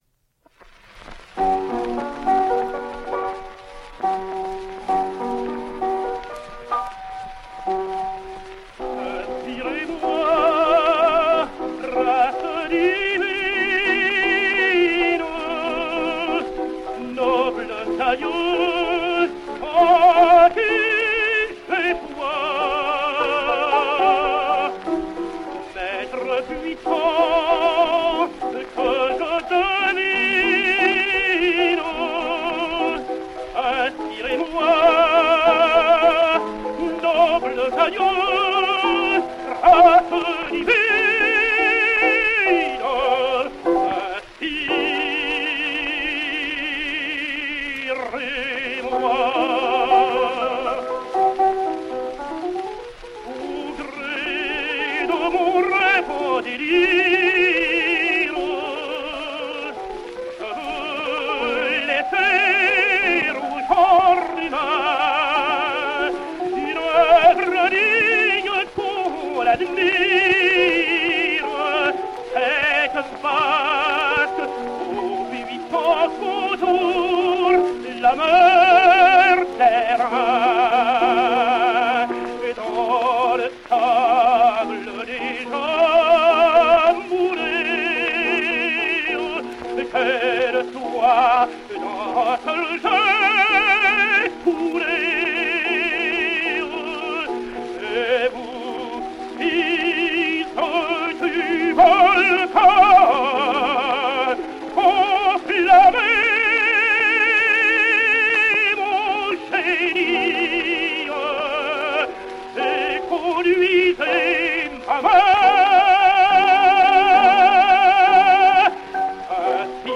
French tenor.
Here he is, in Lend Me Your Aid from Gounod’s opera, the Queen of Sheba.